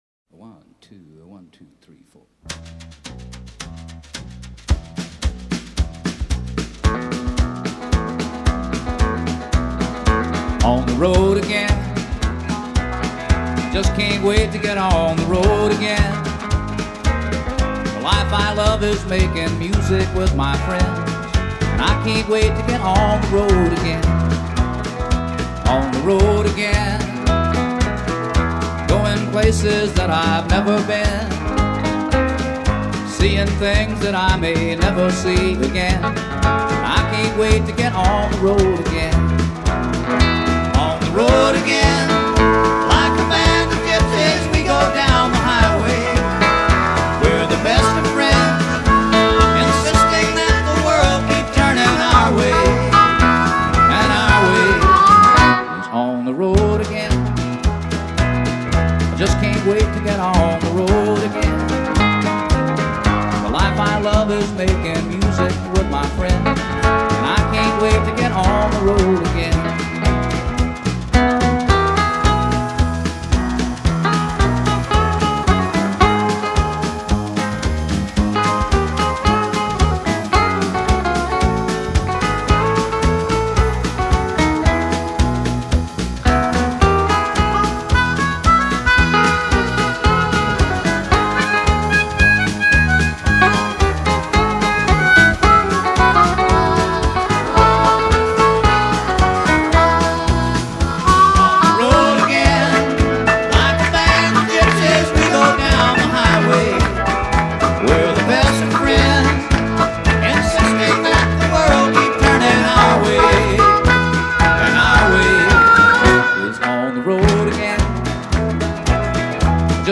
Live at Austin, Texas - Fall 1979
Country, Folk